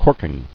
[cork·ing]